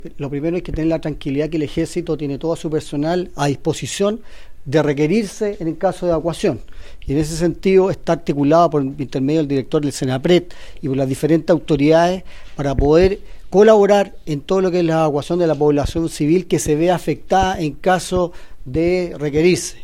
El general José Soto, jefe de la Defensa de La Araucanía, señaló que se ha recorrido 250 rutas y confirmó que el Ejército cuenta con personal capacitado para apoyar ante evacuaciones.